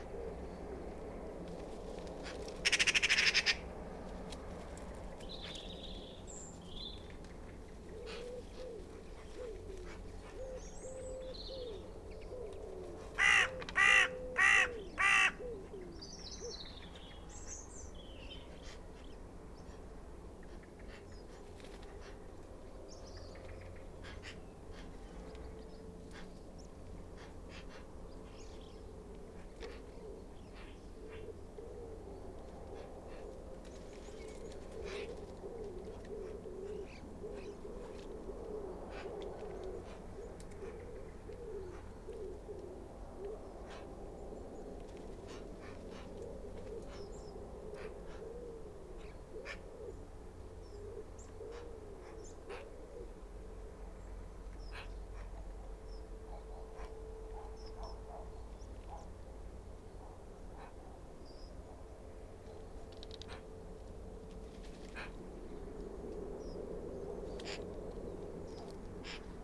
Why the magpie gets such a raw deal in mythology and folkore is perhaps down to the sound it makes, the cackling laughter, and perhaps also because it ties together white and black, day and night, good and bad, light and shade…either way it’s just a bird, a corvid, or crow, albeit a clever one.